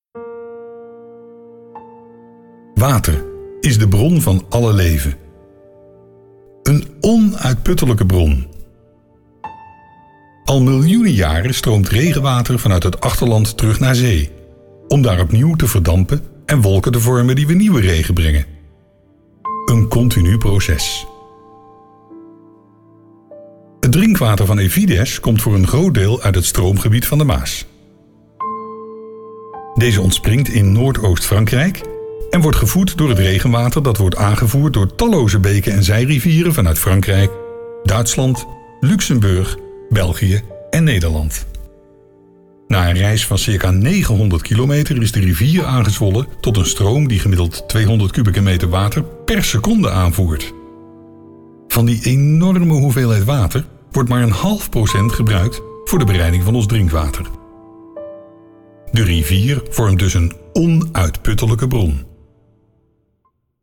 Warm, helder en betrouwbaar, dat zijn de belangrijkste kenmerken van mijn stem.
Hieronder hoort u een voorbeeld van een door mij ingesproken documentaire tekst.
Fragment uit een documentaire over water